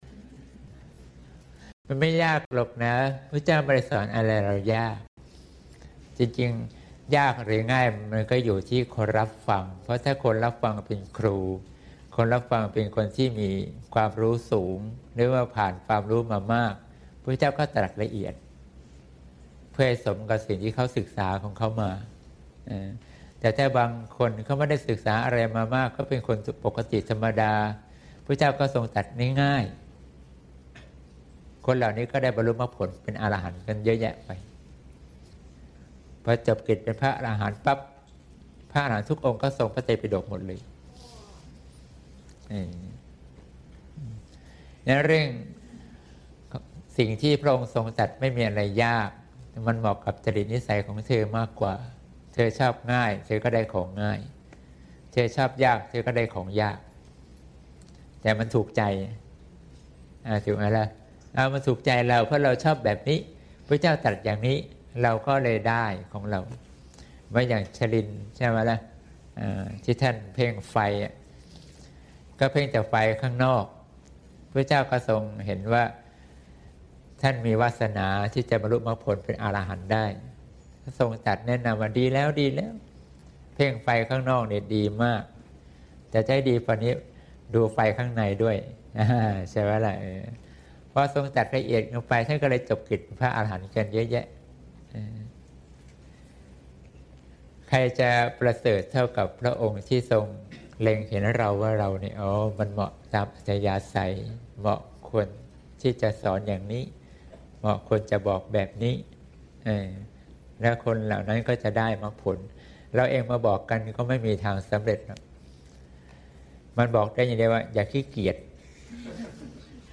เสียงธรรม